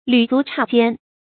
履足差肩 注音： ㄌㄩˇ ㄗㄨˊ ㄘㄧ ㄐㄧㄢ 讀音讀法： 意思解釋： 足與足，肩與肩相接近，形容極親近。